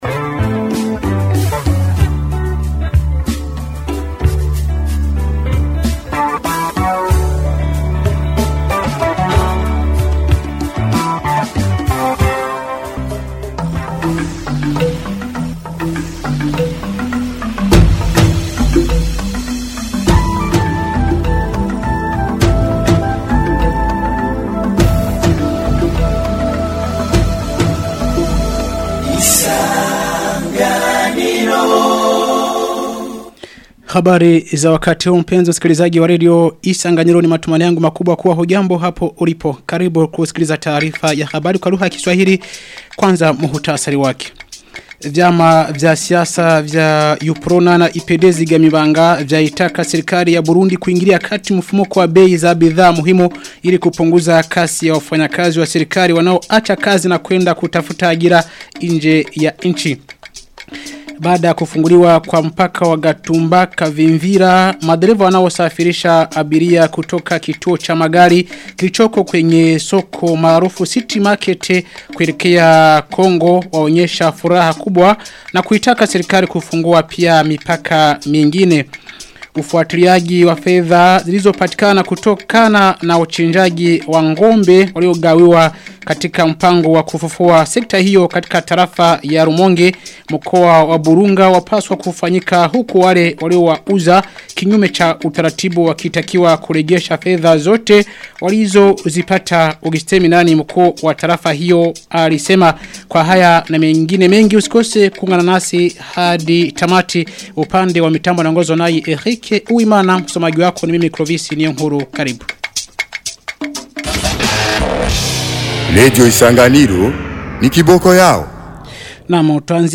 Taarifa ya habari ya tarehe 24 Februari 2026